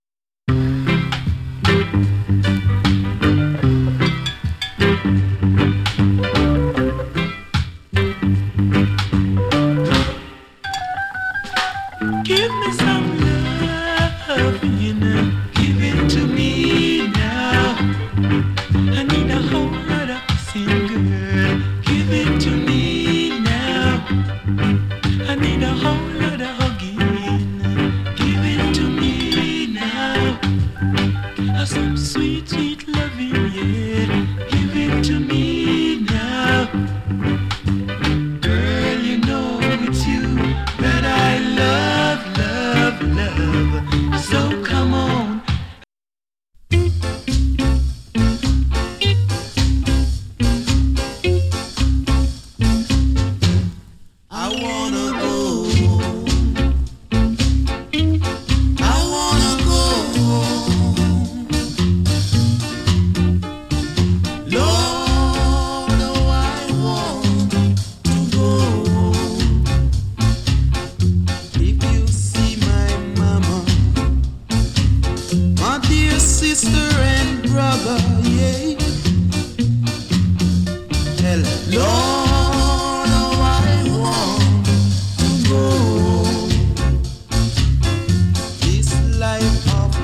NICE ROCK STEADY